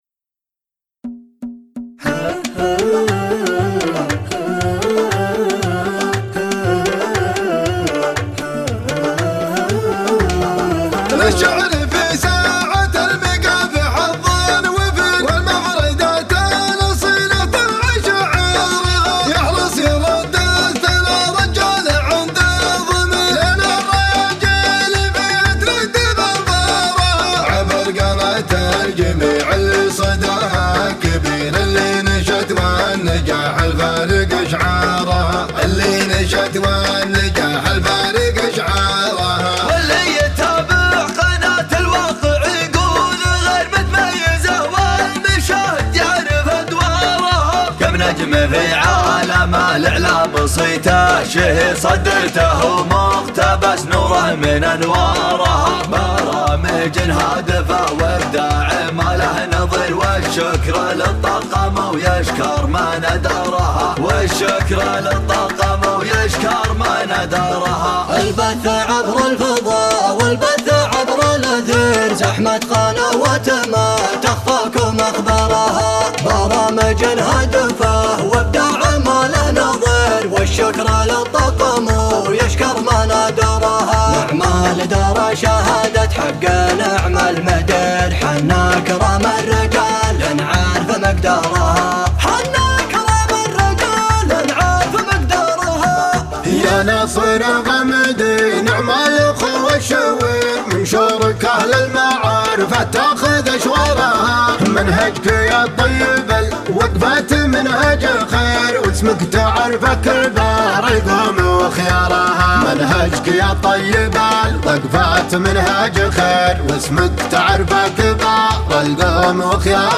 البصمه الحفل الختامي